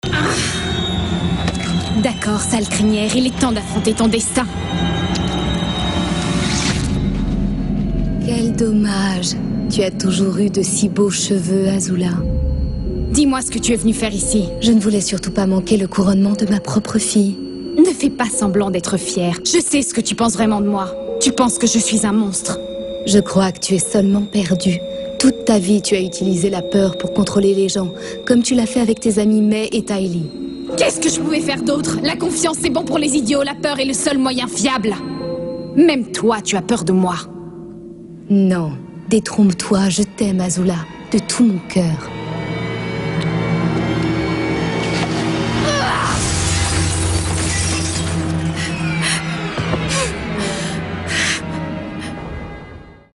Comédienne et chanteuse,je fais des voix régulièrement (doublage,pub,habillage radio et TV) parallèlement à mes activités sur scène.
Sprechprobe: eLearning (Muttersprache):